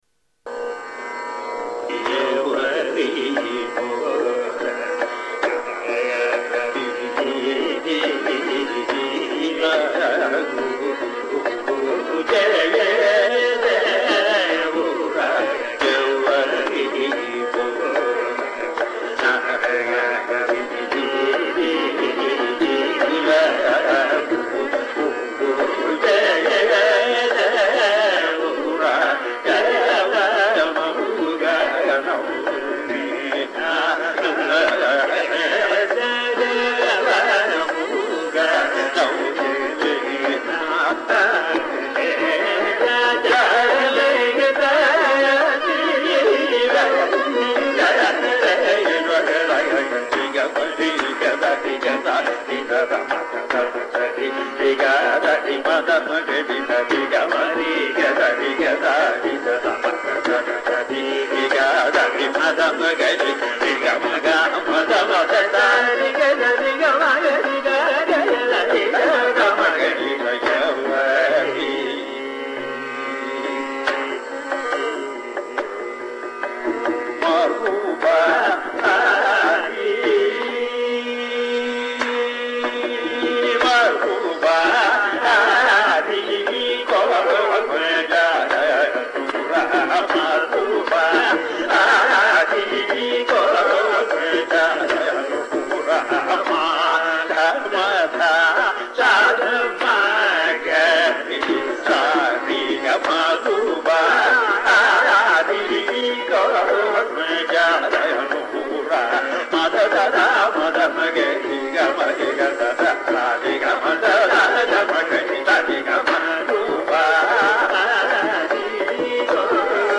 Learning in Carnatic music takes place at two levels.
A varnam in Ragam Abhogi